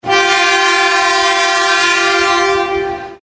Shinkansen horn.mp3
Shinkansen_horn.mp3